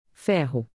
With plenty of examples and audio from a native speaker, you’ll be able to practice your pronunciation and see how accents are used in various contexts.
ferro  (iron), the letter e will sound like in the English word “better.” That would be the typical e sound in the Portuguese language.